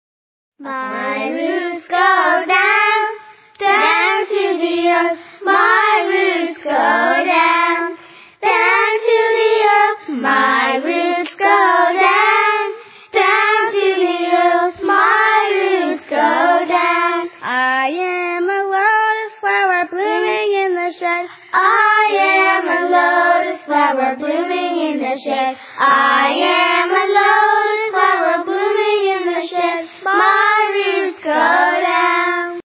lively story-songs